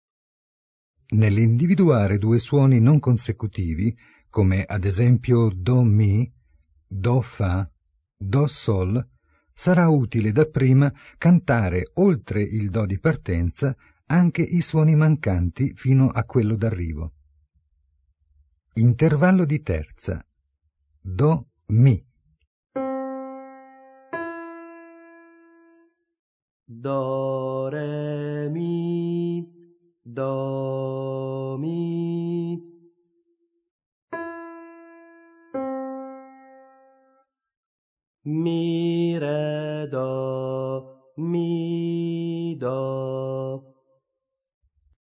Ascoltate i consigli del Maestro e poi cantate insieme allo studente.